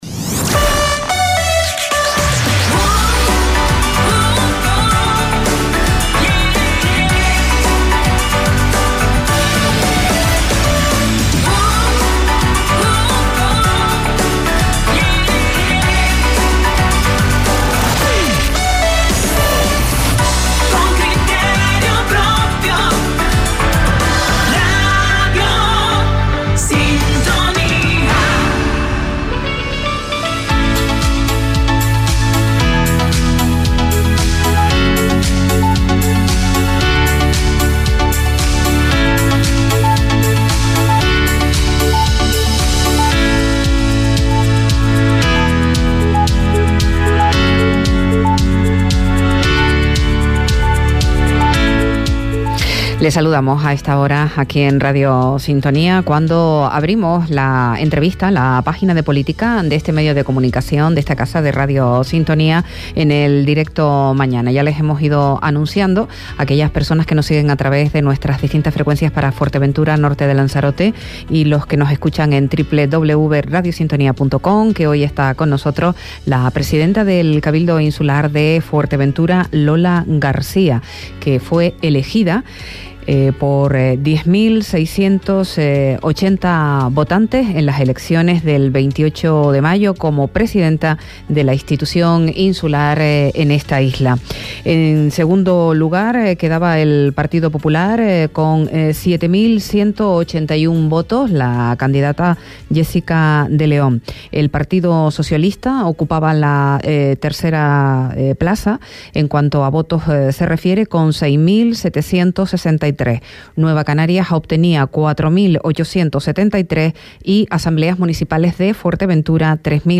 Entrevista a Lola García, presidenta del Cabildo de Fuerteventura - 27.06.23 - Radio Sintonía